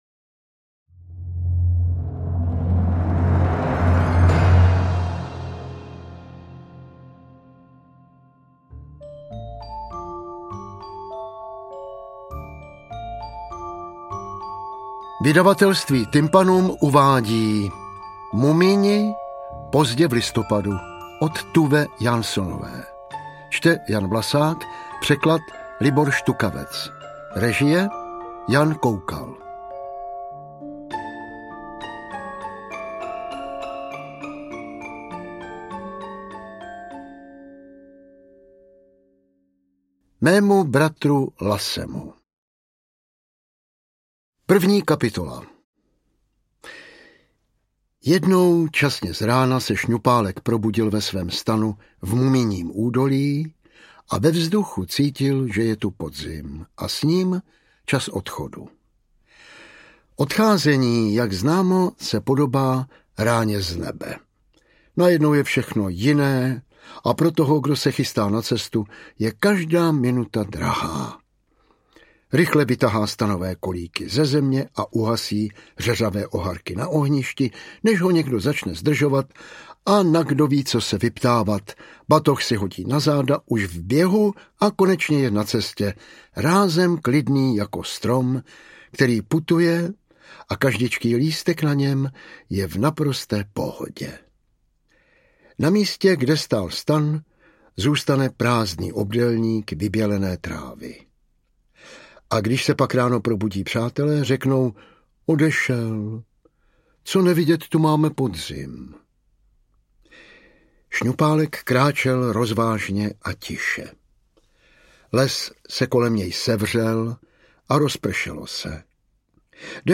Interpret:  Jan Vlasák
AudioKniha ke stažení, 21 x mp3, délka 4 hod. 14 min., velikost 233,3 MB, česky